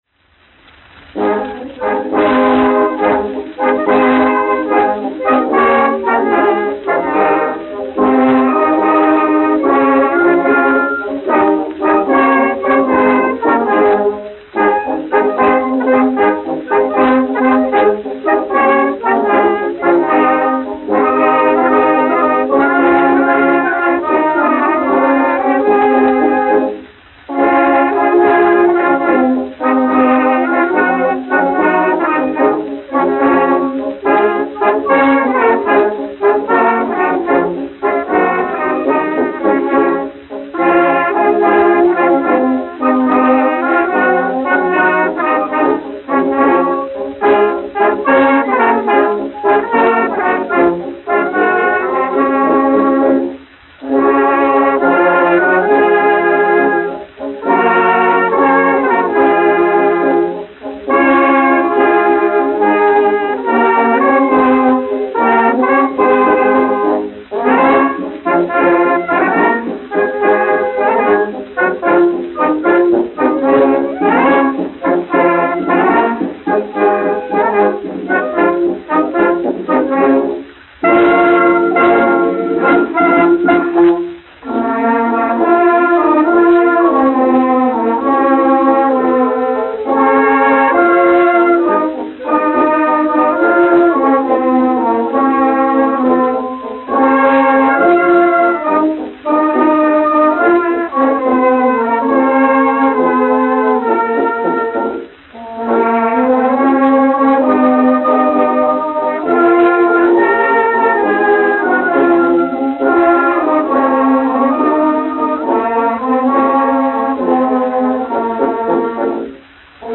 Latvijas 4. Valmieras kājnieku pulka orķestris, izpildītājs
1 skpl. : analogs, 78 apgr/min, mono ; 25 cm
Pūtēju orķestra mūzika
Latvijas vēsturiskie šellaka skaņuplašu ieraksti (Kolekcija)